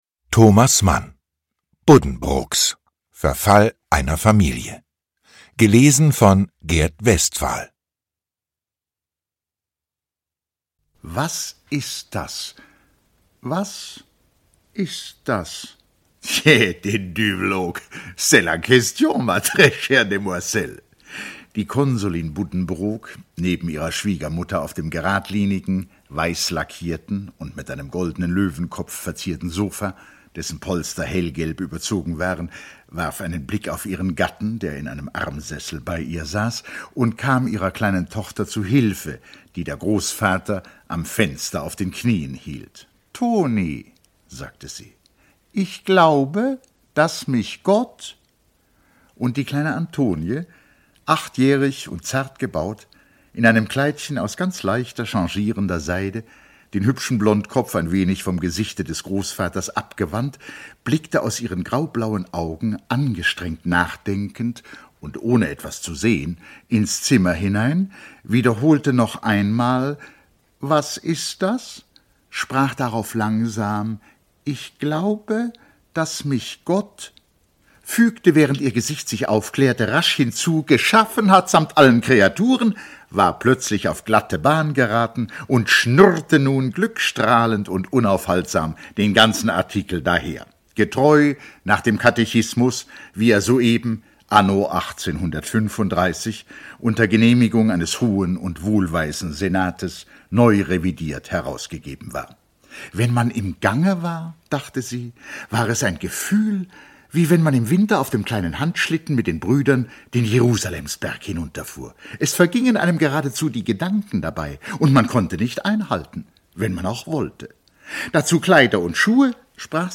Lesung mit Gert Westphal (3 mp3-CDs)
Gert Westphal (Sprecher)
Schlagworte CD • Familiengeschichte • Gesellschaft • Hansestadt • Hörbuch • Hörbücher • Kaufmannsfamilie • Klassiker • Lübeck • neuerscheinung 2024 • Tod • Untergang • Weltbestseller